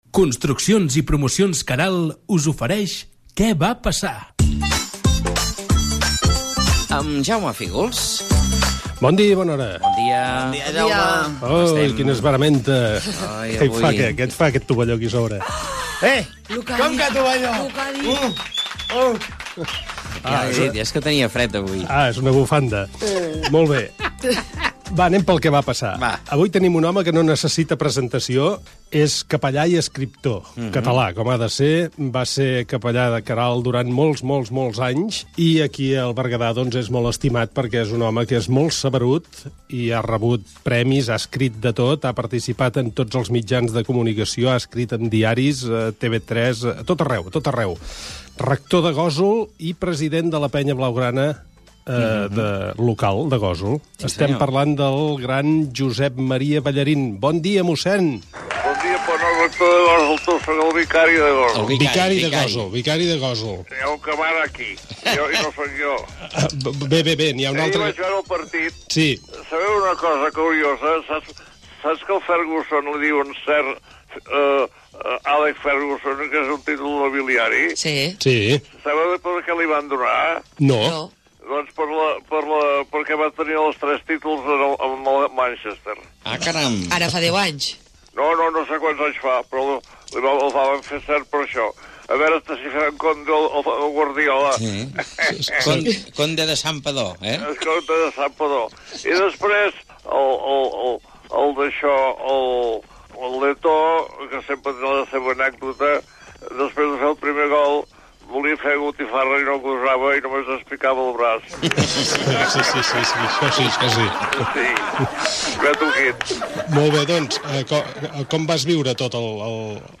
Careta del programa amb publicitat i entrevista telefònica a mossèn Josep Maria Ballarín
Entreteniment